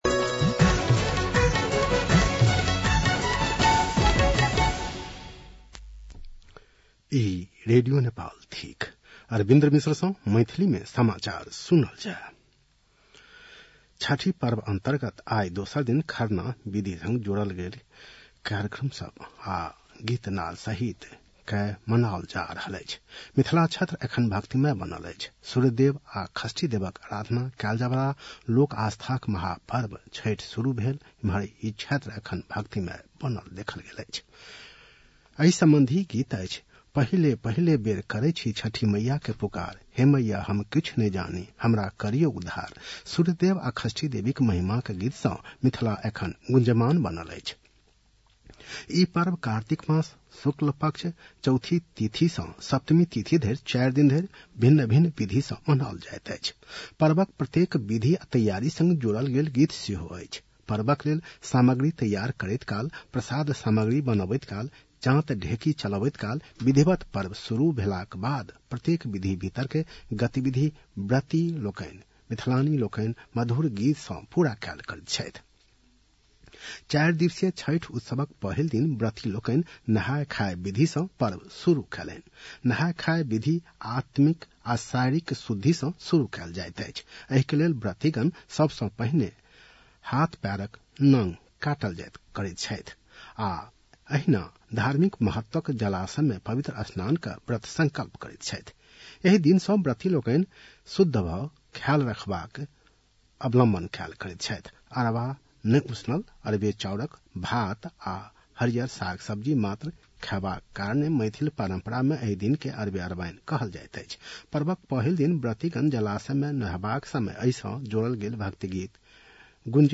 मैथिली भाषामा समाचार : २२ कार्तिक , २०८१